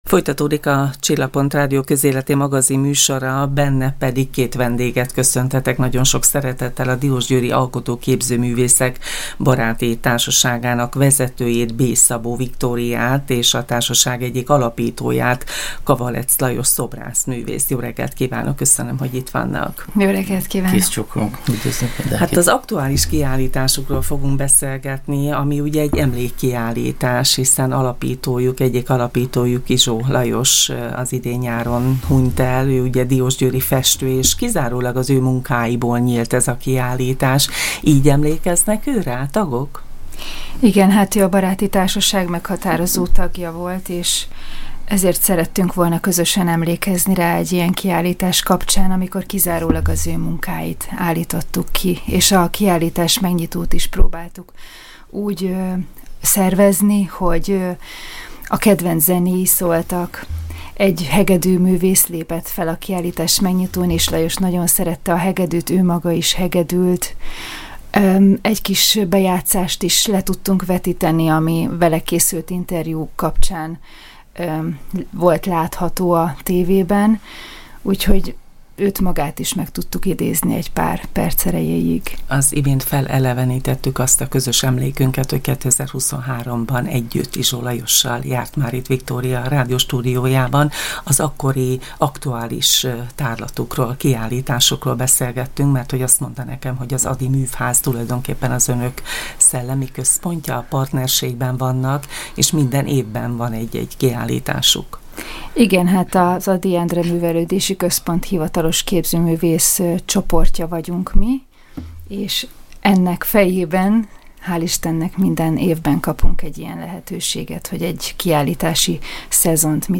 Júniusban elhunyt alkotótársukra emlékeztek stúdióvendégeink